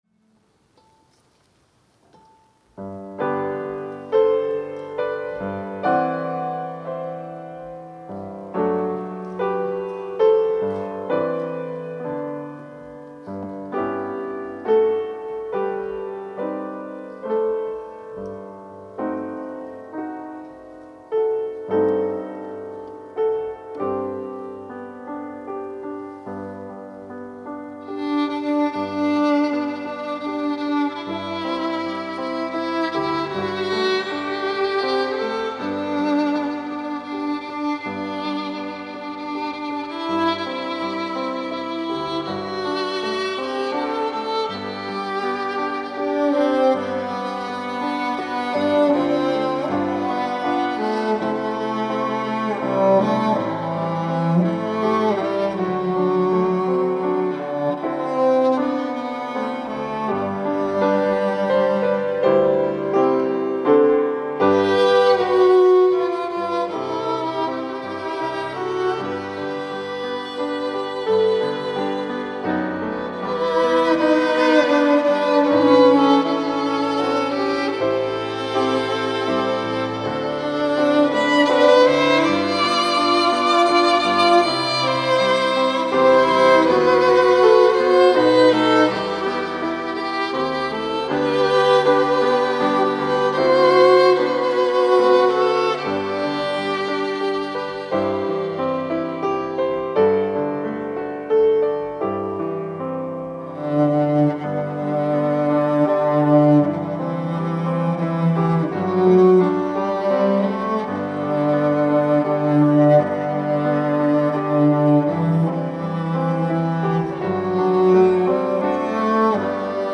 특송과 특주 - 누군가 널 위해 기도하네